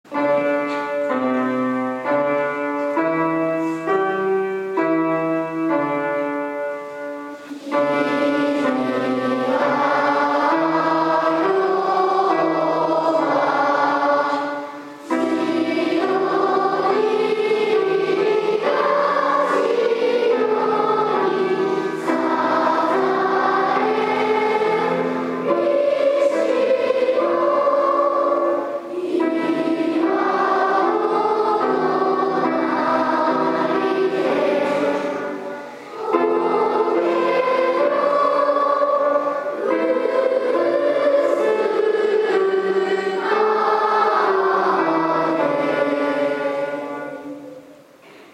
記念式典
国歌斉唱